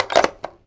phone_put_down_handle_01.wav